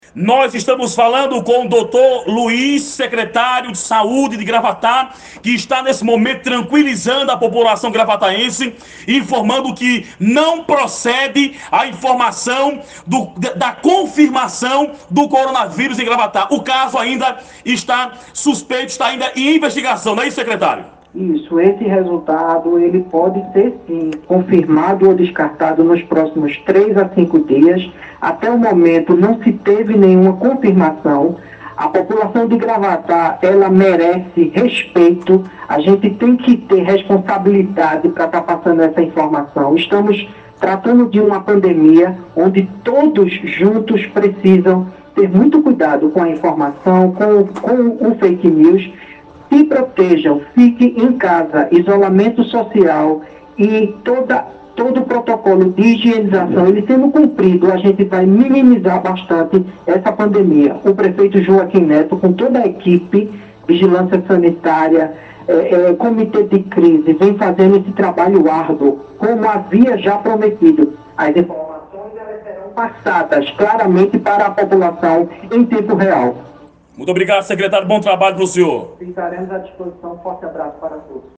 entrevista
levado ao ar pela Rádio Clima FM (98.5 MHz), sempre a partir das 12 horas.